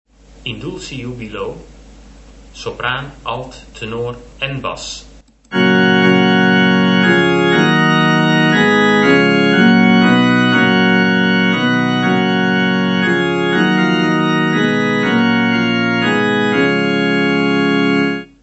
Verticaal: 'In dulci jubilo' : Alle stemmen klinken samen.